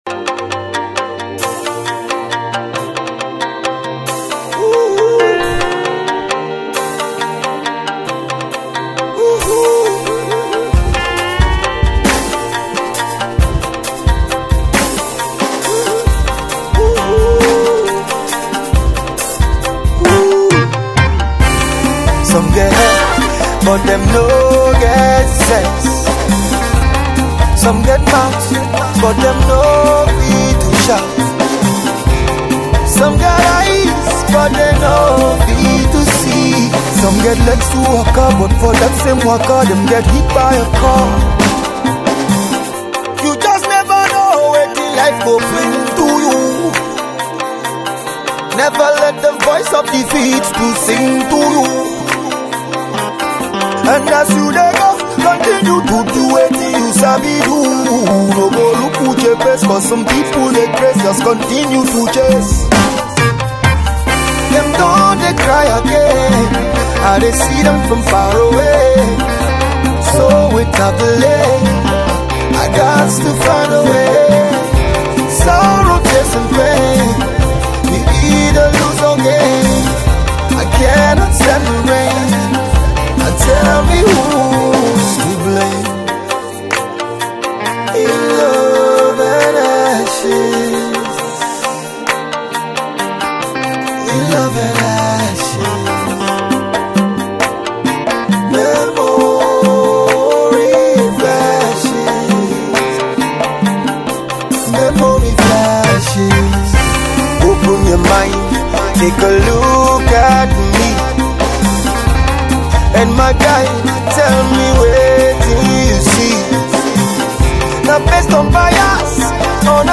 original soundtrack
soul-stirring track with thought-provoking lyrics